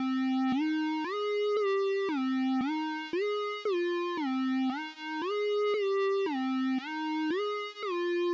不回头》全场大鼓
描述：我为这首令人毛骨悚然的万圣节类型的节拍制作了全鼓，名为"Don't look backquot。
Tag: 115 bpm Trap Loops Drum Loops 2.81 MB wav Key : A